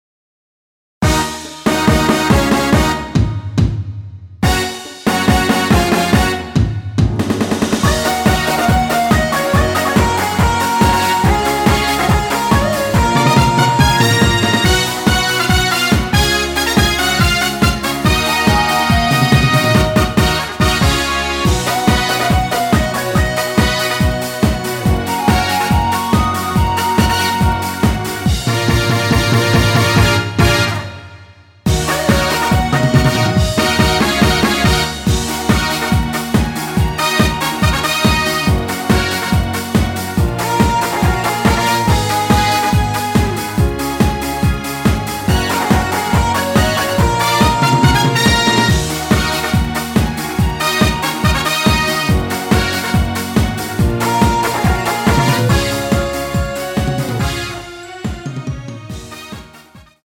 Ebm
앞부분30초, 뒷부분30초씩 편집해서 올려 드리고 있습니다.
중간에 음이 끈어지고 다시 나오는 이유는